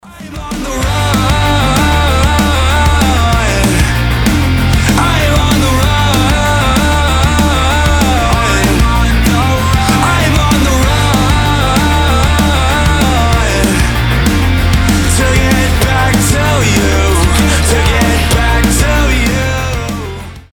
• Качество: 320, Stereo
громкие
Драйвовые
Alternative Rock
Pop Rock
быстрые
поп-панк